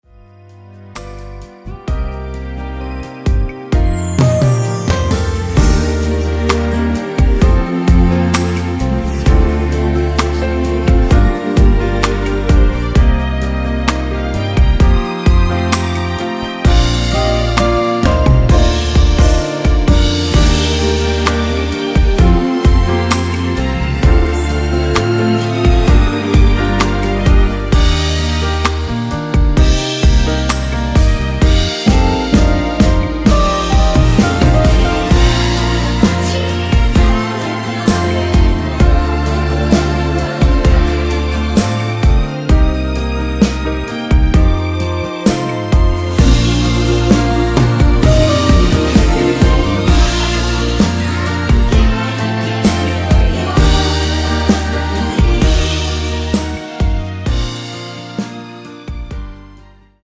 여성분이 부르실수 있는 코러스 포함된 MR 입니다.
Bb
앞부분30초, 뒷부분30초씩 편집해서 올려 드리고 있습니다.